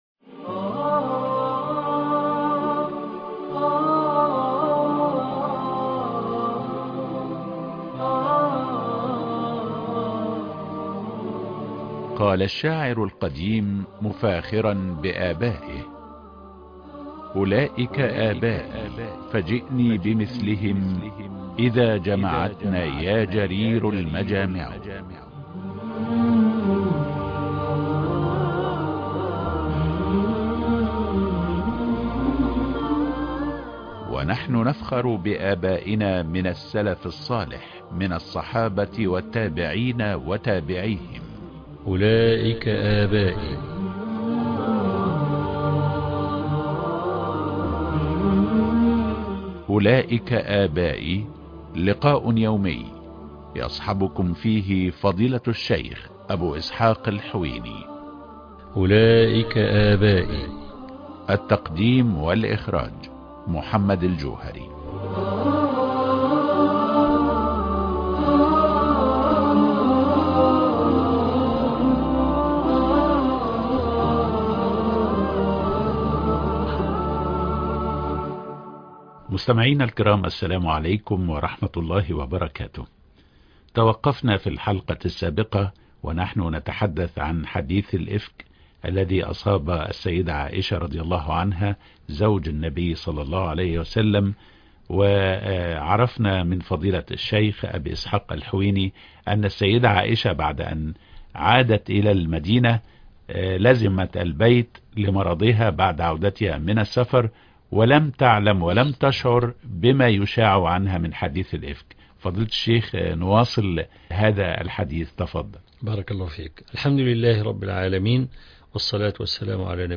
البرنامج الإذاعي